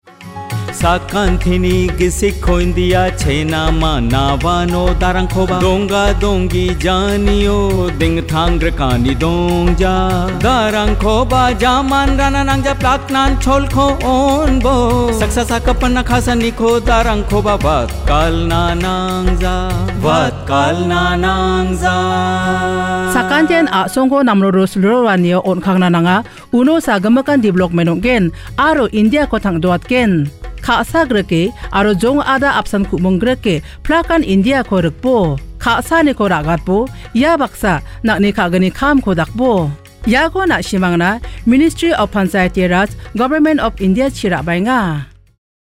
51 Fundamental Duty 5th Fundamental Duty Sprit of common brotherhood Radio Jingle Garo